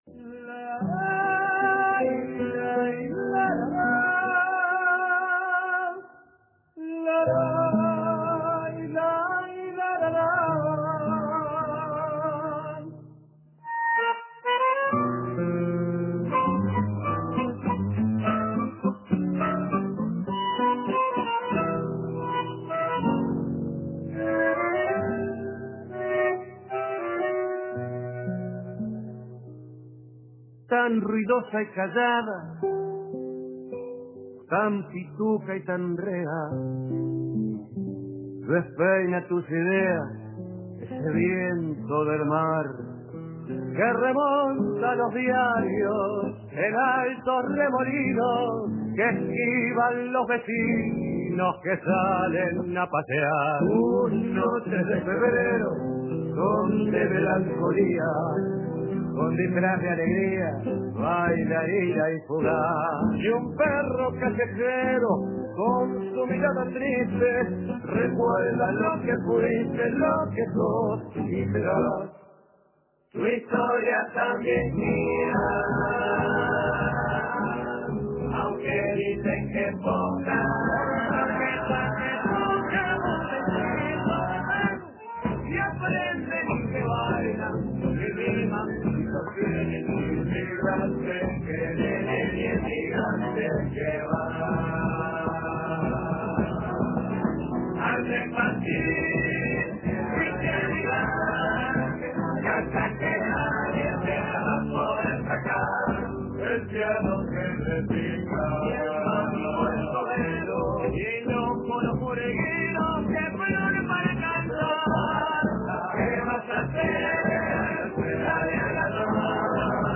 Parte de Los Mareados pasaron por Café Torrado a tomar un cafecito "bien batido".